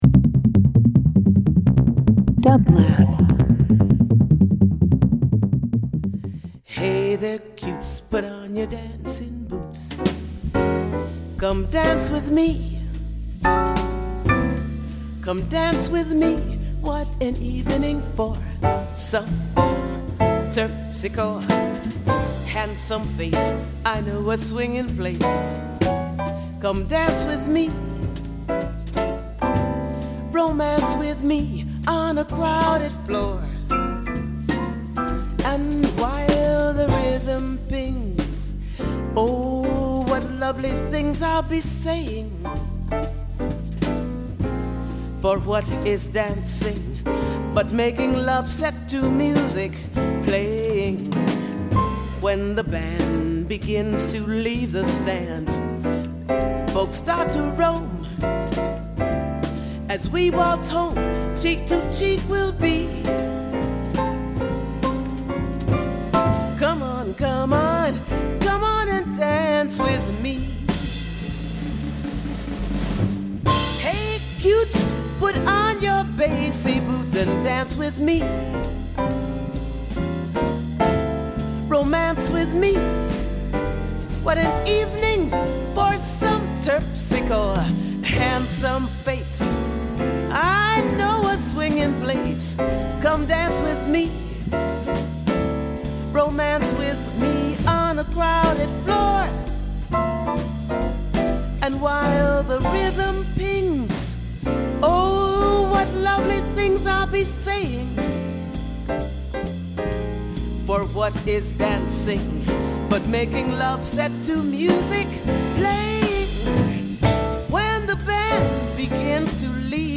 Brazilian Jazz Voice